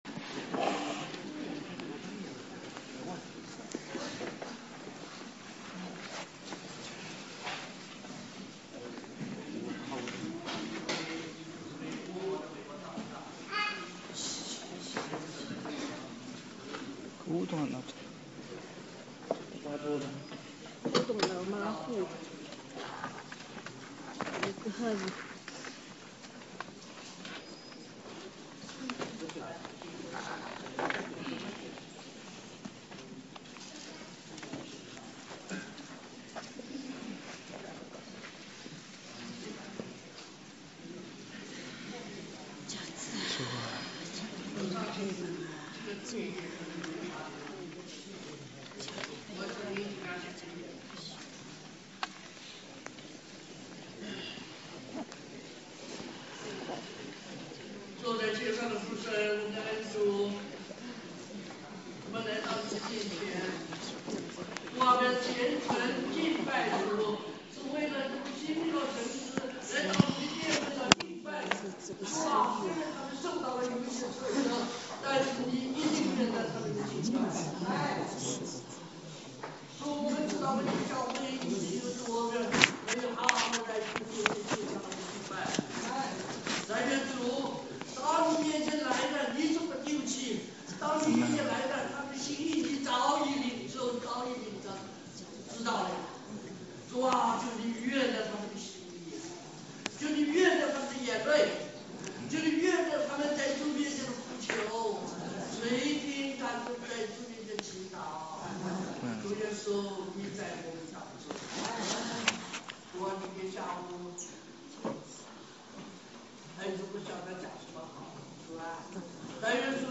2011年2月20日巴黎温州教会主日下午讲道